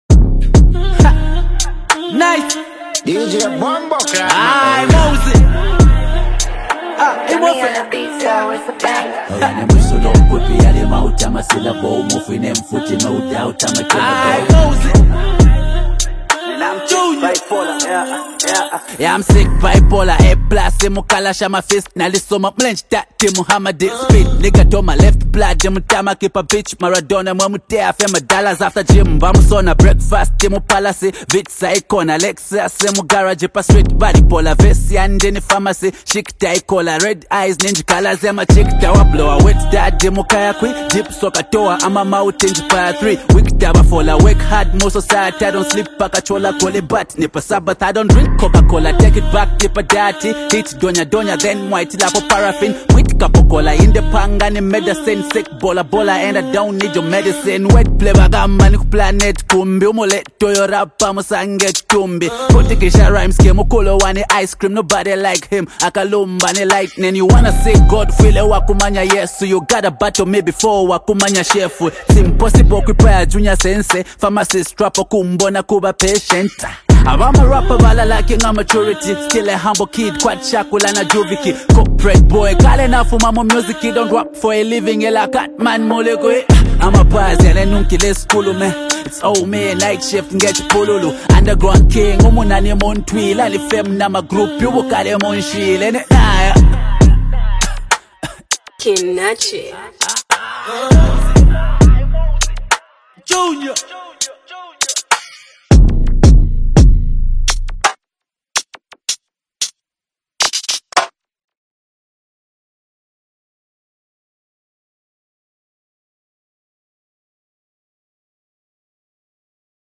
is a lyrically dense and technically driven track
giving the track a fast-paced, almost hypnotic flow